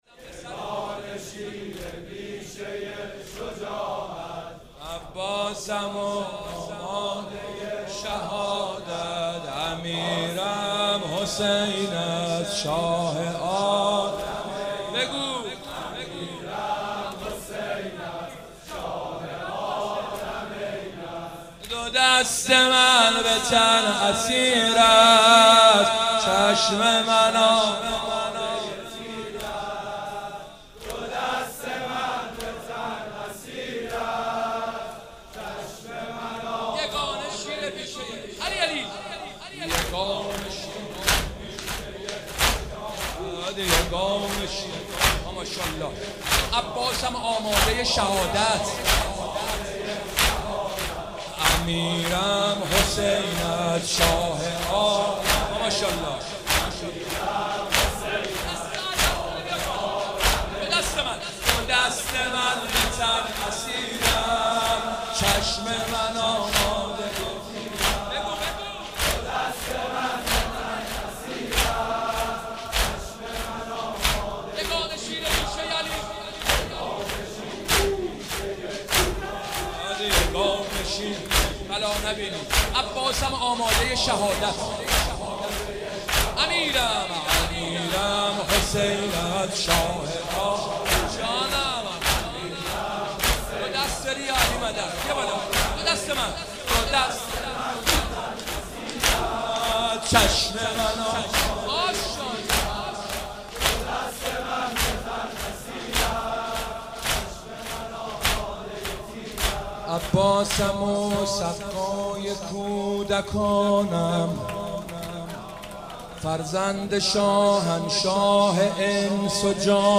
محرم 96 - نوحه - یگانه شیر پیشه ی شجاعت